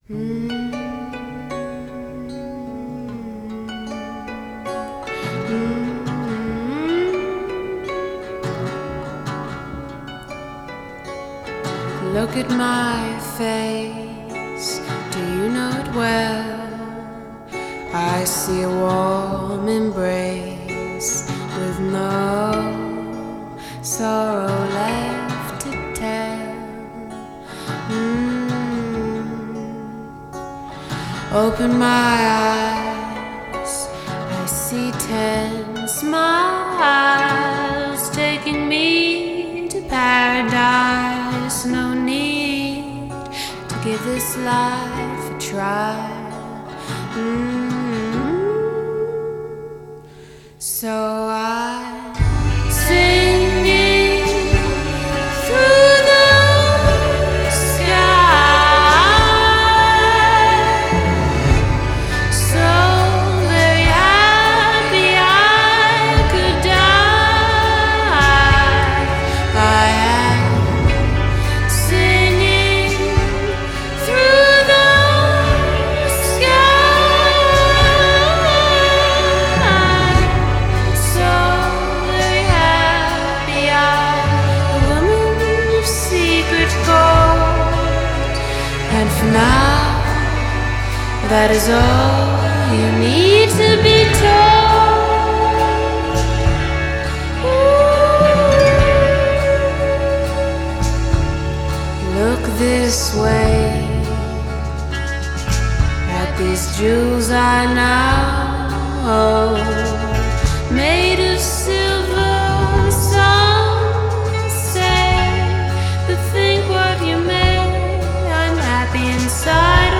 Genre: Indie Folk